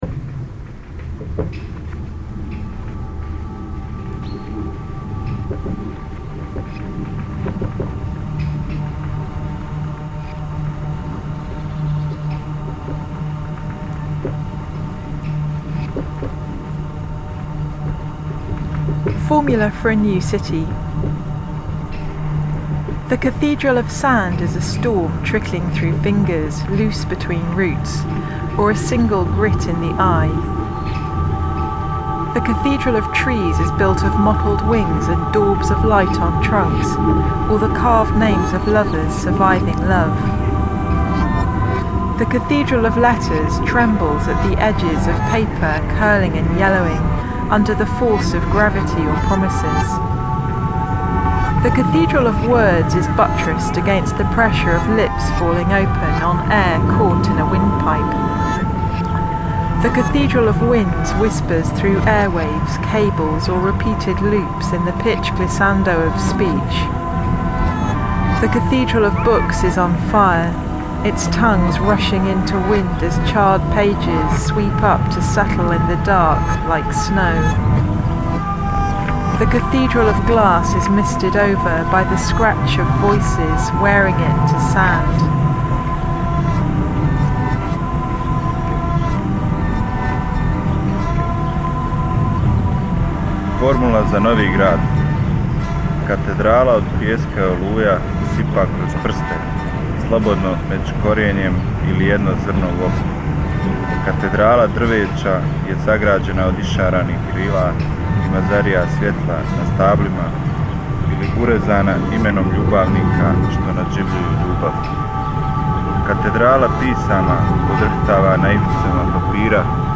psychogeographical experimental music group
in Sarajevo, May 2005
to give a performance in Sarajevo
who performed the Bosnian texts